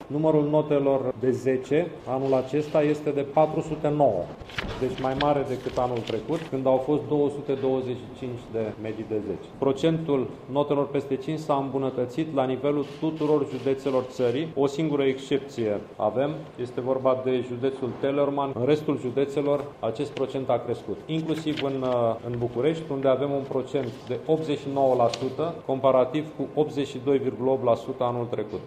Și numărul mediilor de 10 s-a dublat în acest an, mai spune ministrul:
Sorin Cîmpeanu, ministrul Educației.